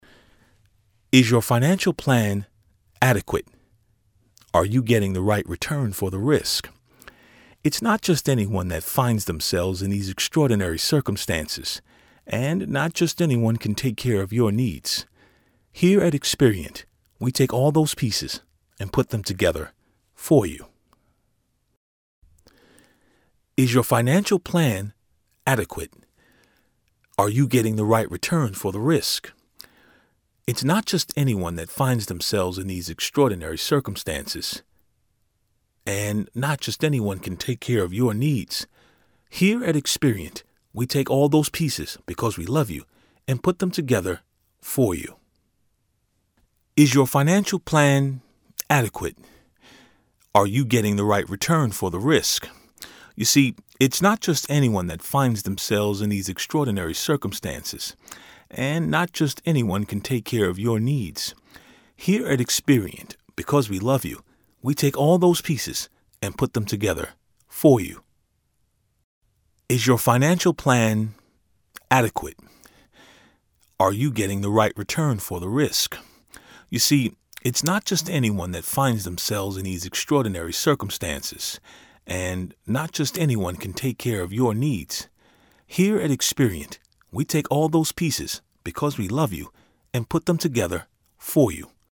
I am a Voice Over artist.
Attached is a read that I did in 4 different locations in my newly treated vocal booth. They are 4 quick reads edited back-to-back.